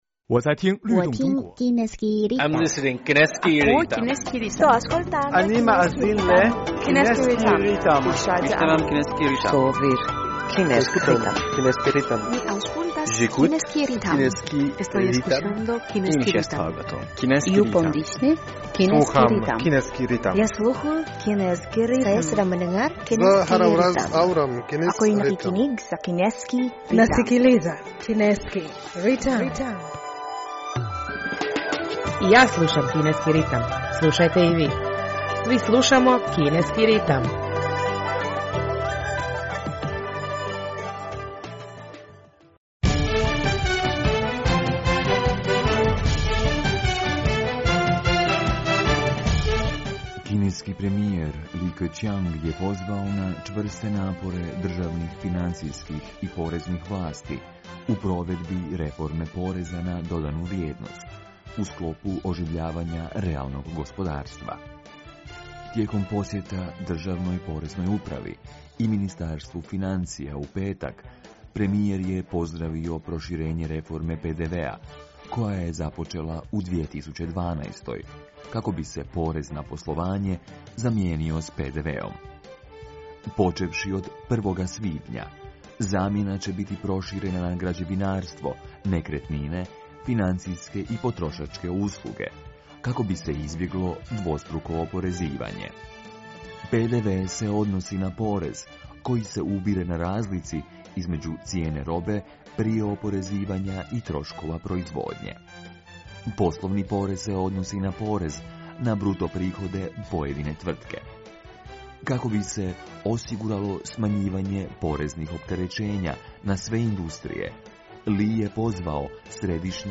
U današnjoj emisiji prije svega poslušajte novosti iz Kine i svijeta, a zatim našu rubriku "U fokusu Kine".